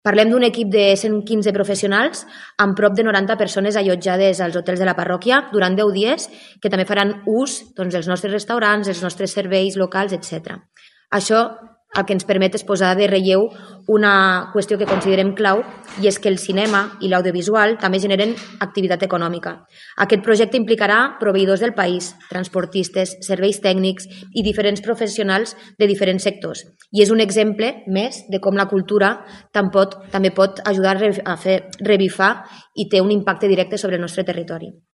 Per la seva banda, la cònsol menor de Sant Julià de Lòria, Sofia Cortesao, ha detallat que bona part de l’equip s’allotjarà a la parròquia durant el rodatge.